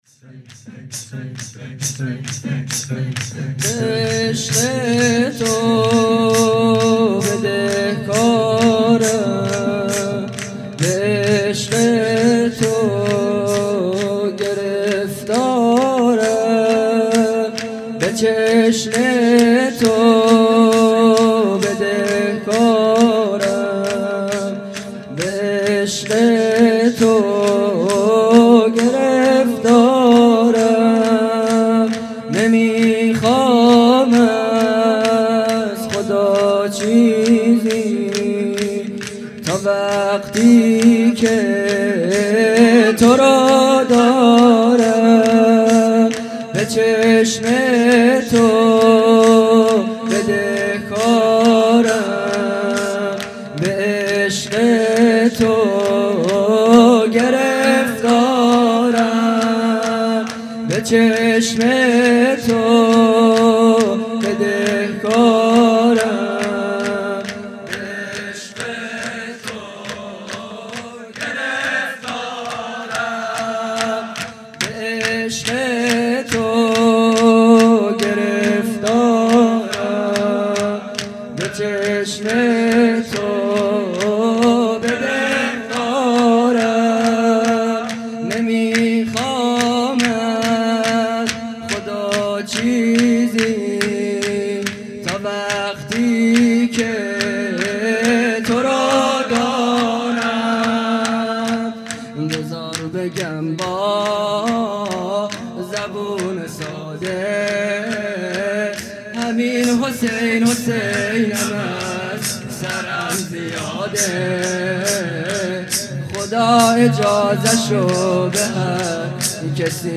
خیمه گاه - هیئت بچه های فاطمه (س) - شور | به عشق تو بدهکارم
جلسه هفتگی